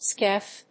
シーエス‐エフブイ